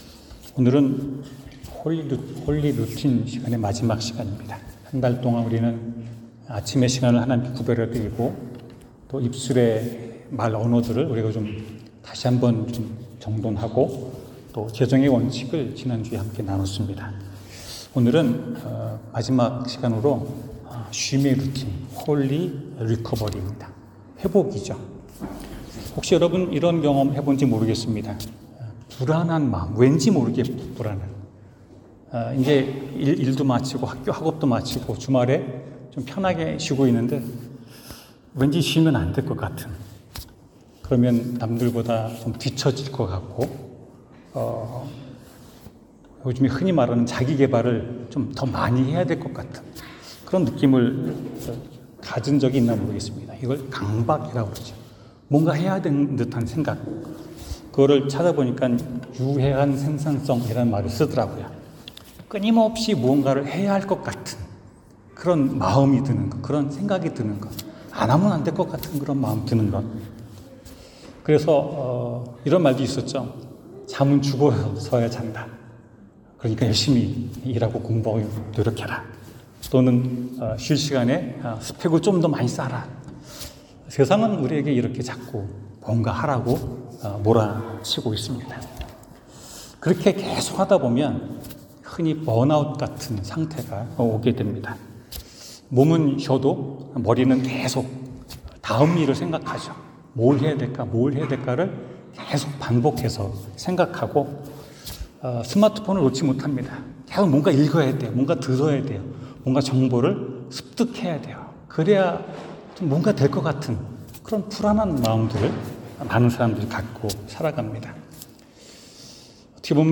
쉼의 루틴 성경: 창세기 2:3 설교